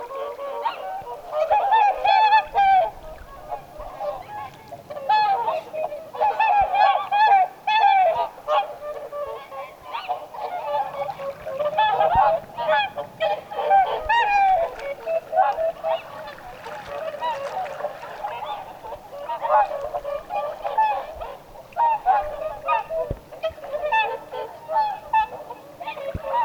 yksinäinen poikanen ääntelee
tavallista korkeammalla äänellään?
ilm_yksinainen_poikanen_aantelee_tuolla_tavoin.mp3